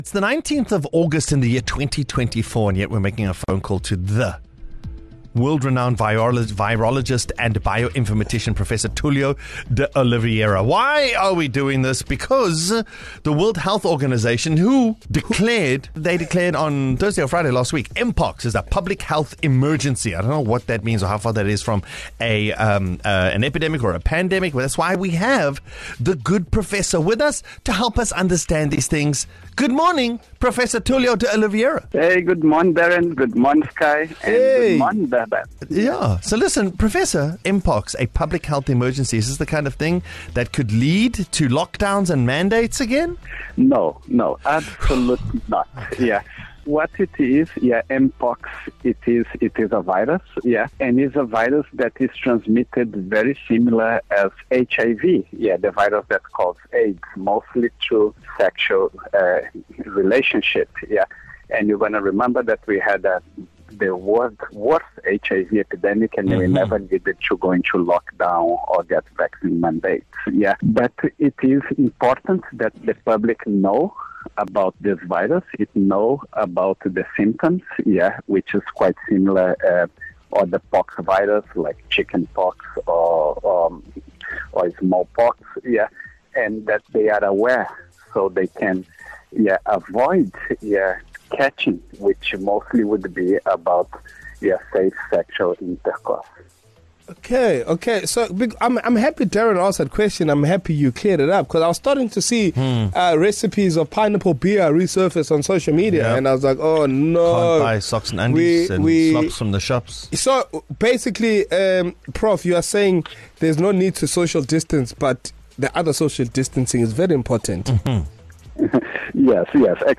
East Coast Breakfast phoned World renowned virologist, and Bio-informatician, Prof. Tulio De Oliveira to ask him if we should be worried about another lockdown, should we wear masks, and should we be afraid of M-Pox!